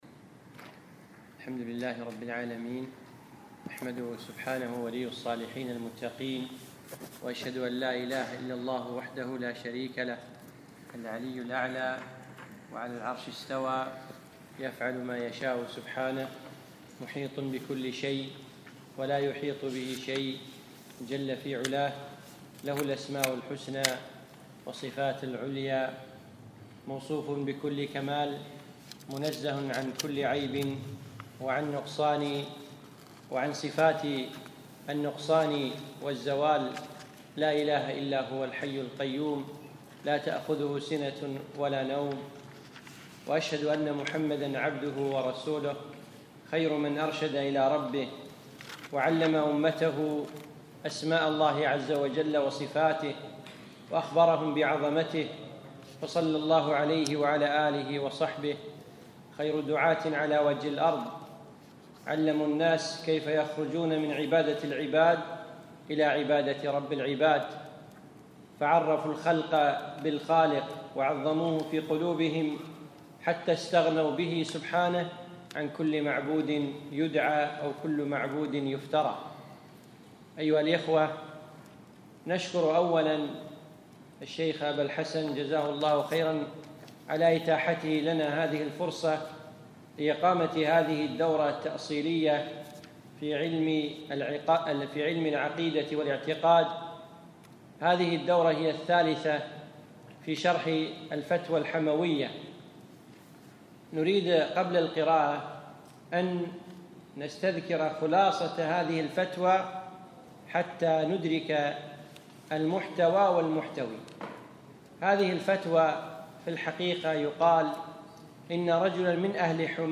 شرح الفتوى الحموية لشيخ الاسلام ابن تيمية الدرس الاول